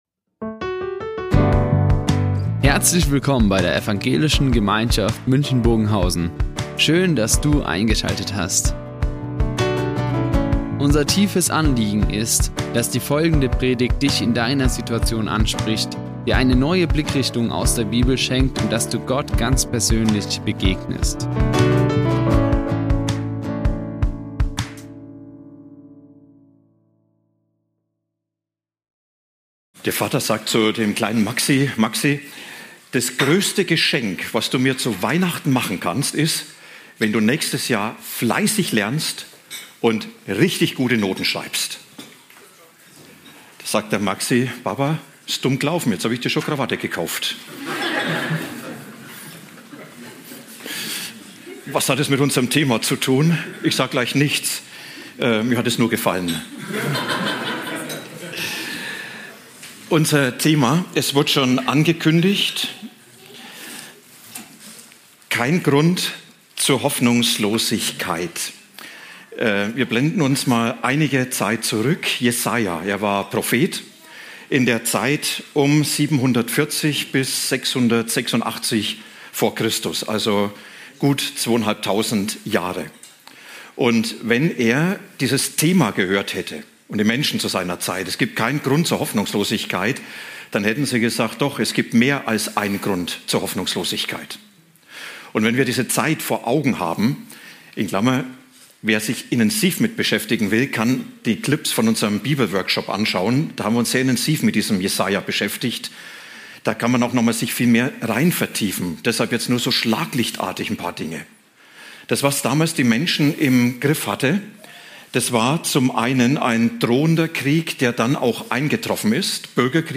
Kein Grund für Hoffnungslosigkeit | Predigt Jesaja 35,3-10 ~ Ev.